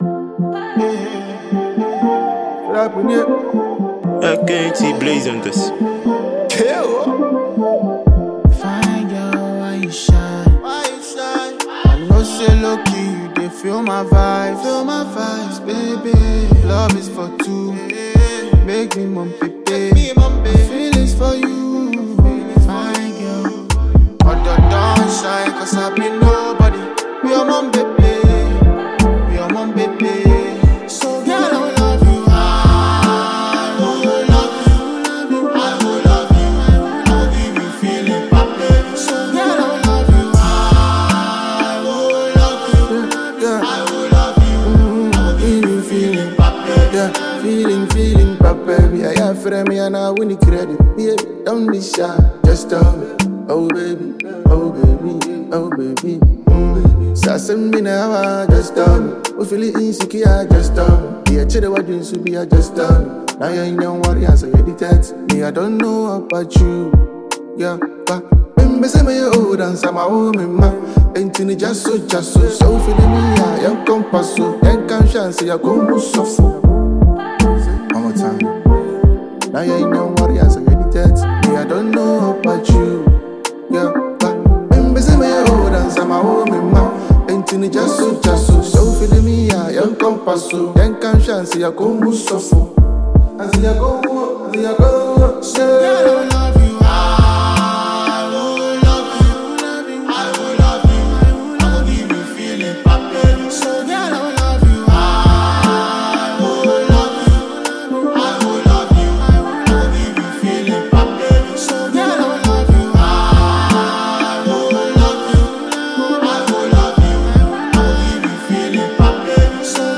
Ghanaian Afro Fusion and Hiplife artiste